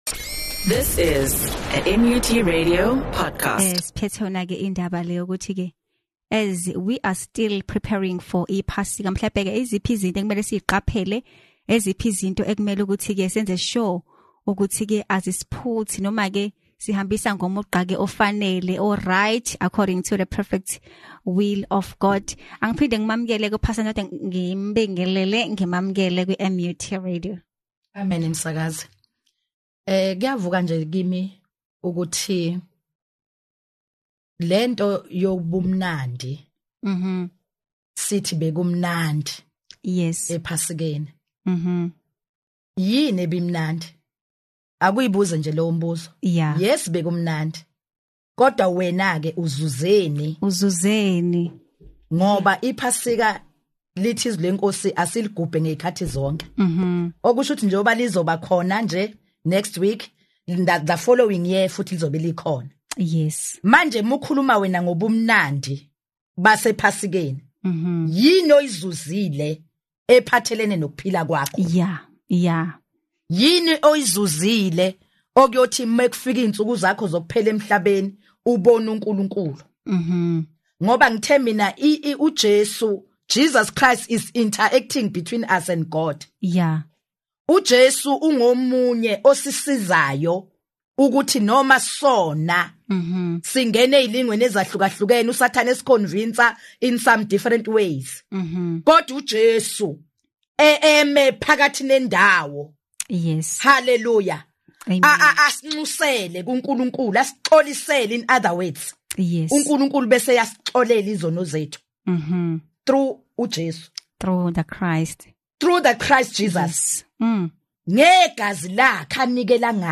Interfaith